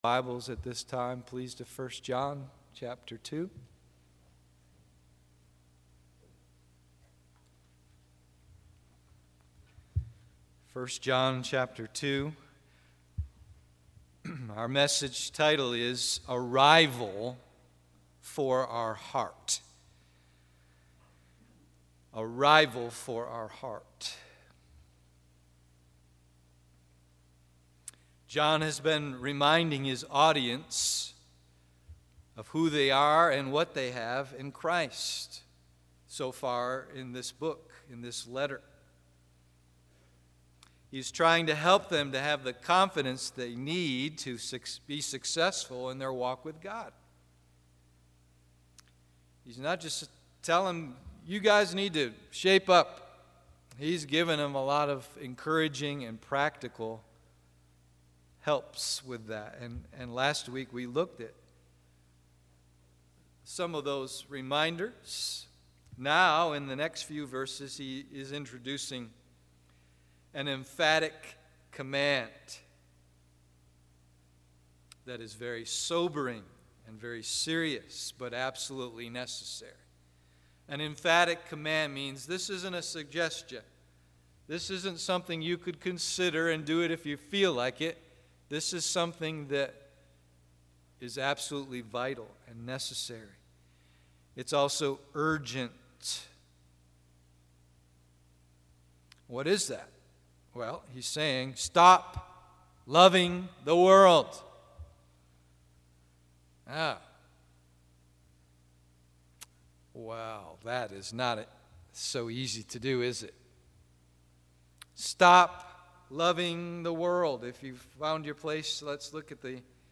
A Rival for Our Hearts AM Service